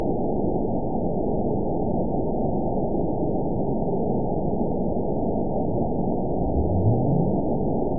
event 922290 date 12/29/24 time 04:17:04 GMT (4 months ago) score 9.56 location TSS-AB04 detected by nrw target species NRW annotations +NRW Spectrogram: Frequency (kHz) vs. Time (s) audio not available .wav